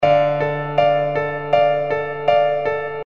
描述：解释：！创造你的钢琴！这里有所有的C调钢琴和弦。这里有所有C大调的钢琴和弦，用这些曲子你可以创造你自己的钢琴曲。构建你喜欢的钢琴旋律。玩得开心 )
Tag: 80 bpm Hip Hop Loops Piano Loops 516.84 KB wav Key : Unknown